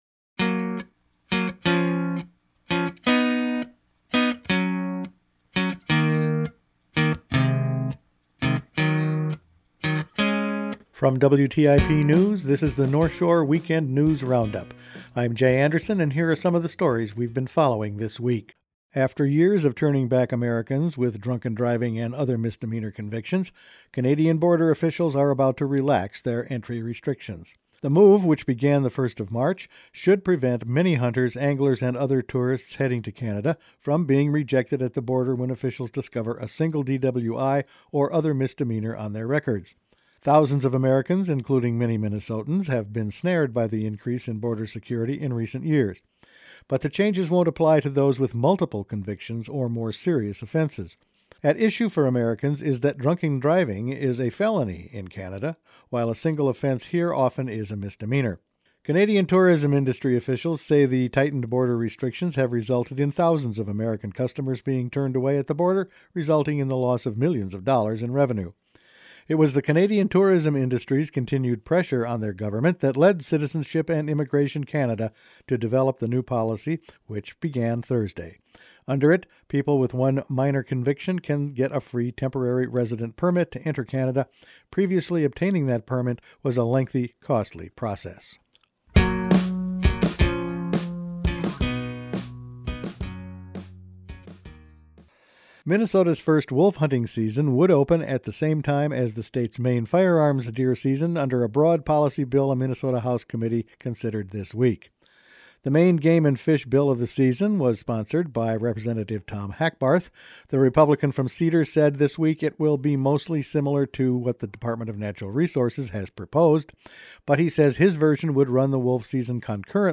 Weekend News Roundup for March 3
Each weekend WTIP news produces a round up of the news stories they’ve been following this week. Changes at the Canadian border crossing, legislation on a wolf season, no Supreme Court action on Asian carp and Rep. Dill no longer represents Two Harbors…all in this week’s news.